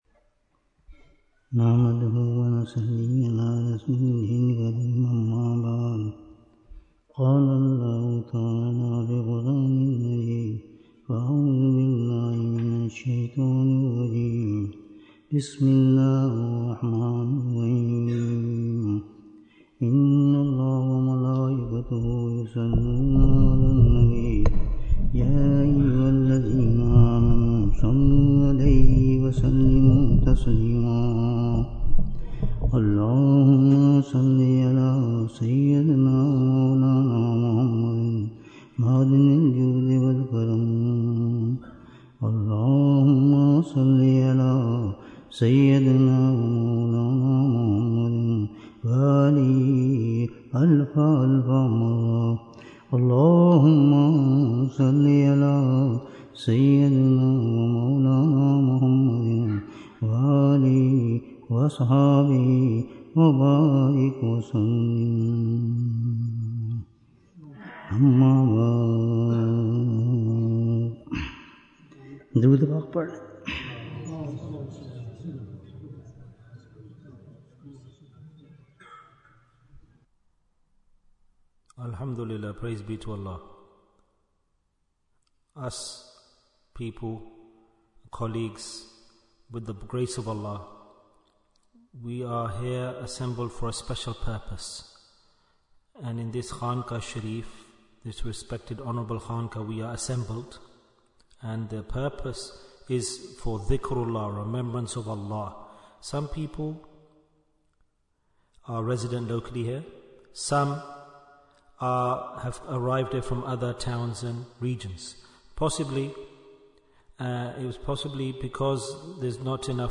Majlis-e-Dhikr in Burnley Bayan, 77 minutes12th January, 2025
Bayan